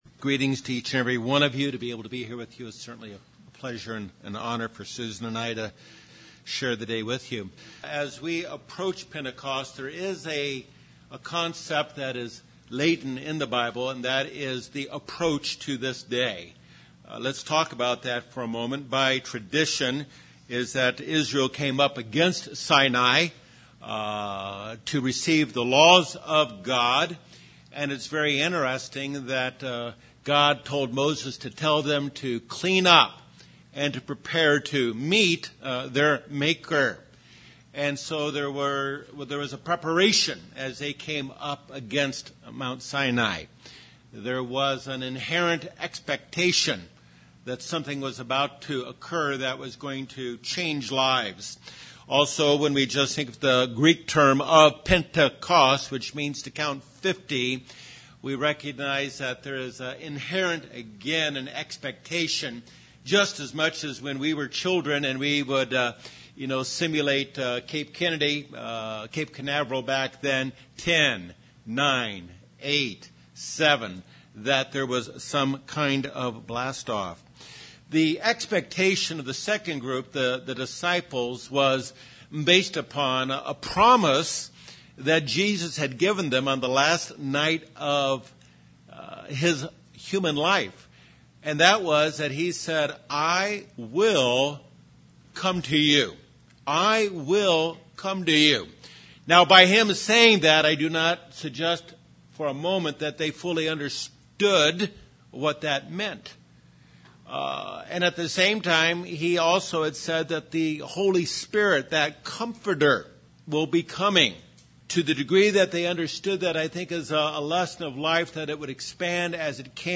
Jesus ascended as the first fruits. First fruits put first things first and do not have a postponement mentality. (some poor audio)